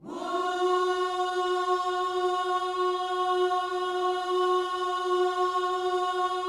WHOO F#4A.wav